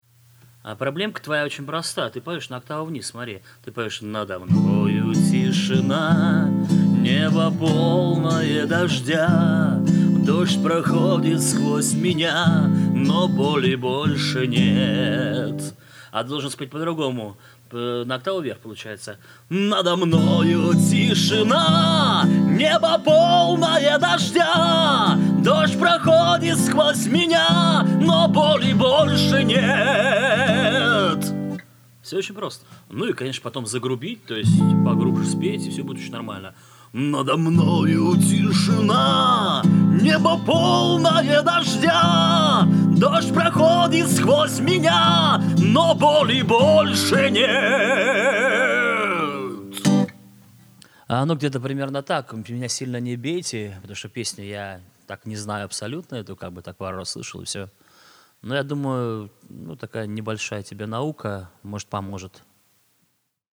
Вокал. Демо